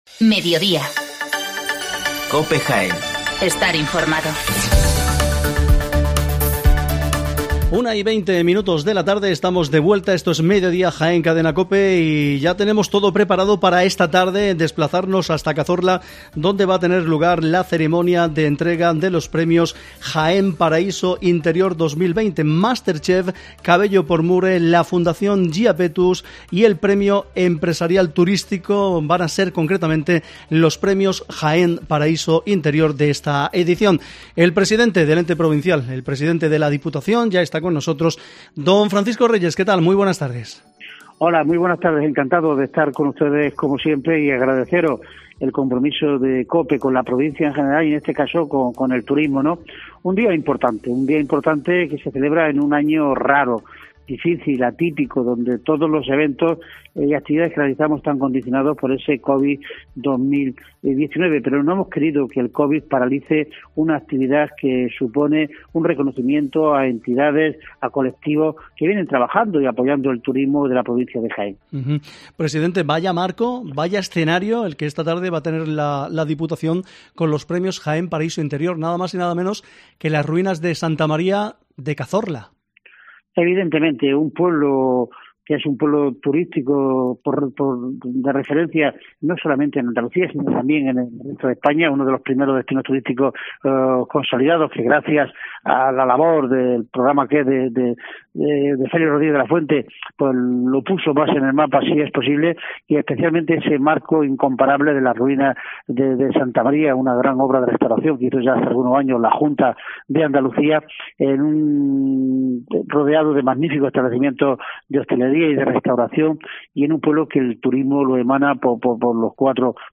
AUDIO: Francisco Reyes, presidente de Diputación, nos da los detalles de los premios 2020